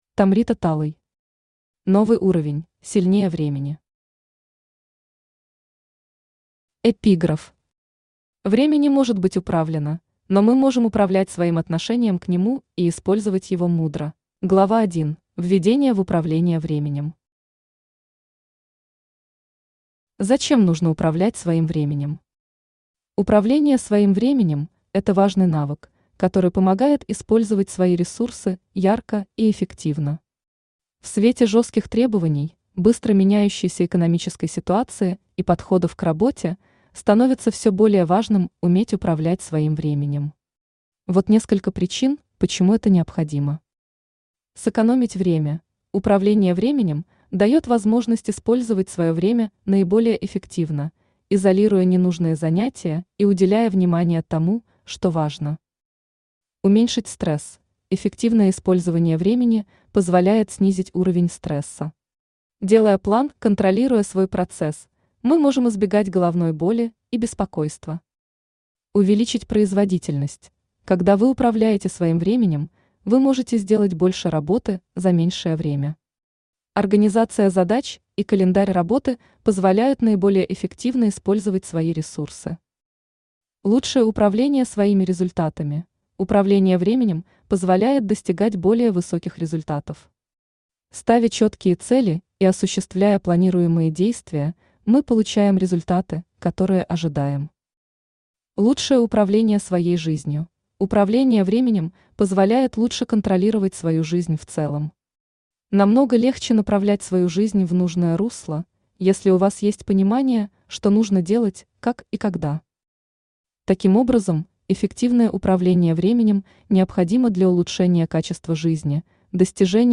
Aудиокнига Новый уровень: Сильнее времени Автор Tomrita Talay Читает аудиокнигу Авточтец ЛитРес.